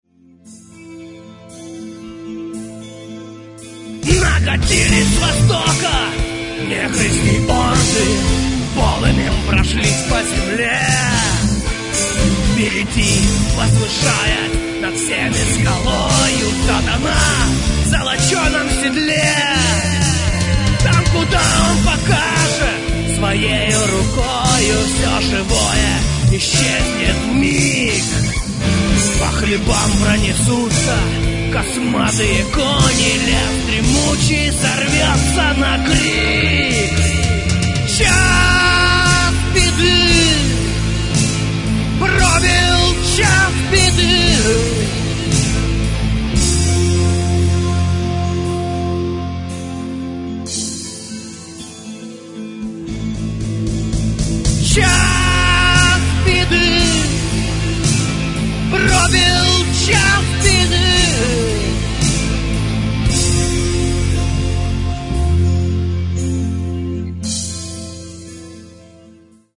Гитары, бас, клавиши, перкуссия, вокал
фрагмент (411 k) - mono, 48 kbps, 44 kHz